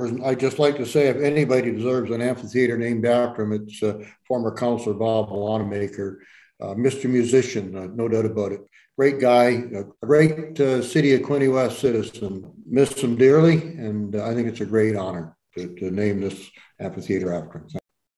Before the committee voted unanimously in favour of the recommendation some members sang the praises of the former councillor.
Councillor David McCue said the honour is well deserved.